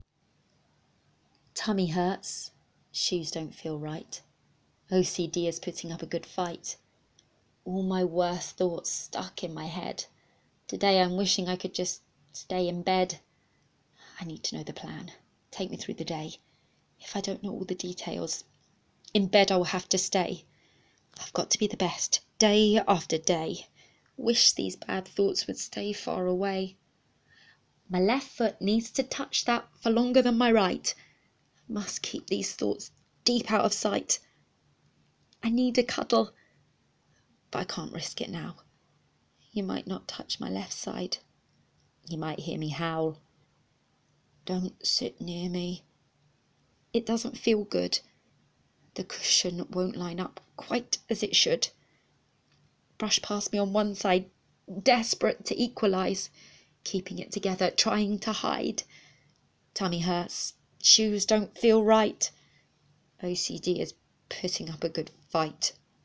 anon1-poem.wav